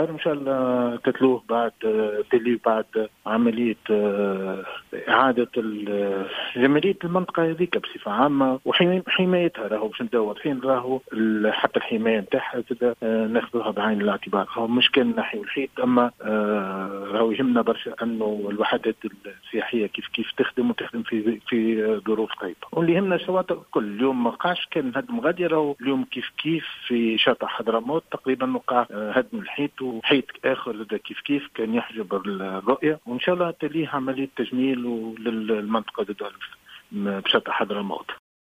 تم اليوم الخميس تنفيذ قرارات هدم و إزالة على الملك العمومي البحري بشاطئي بوجعفر وحضرموت وفق ما أكده رئيس بلدية سوسة محمد إقبال خالد في تصريح للجوهرة "اف ام".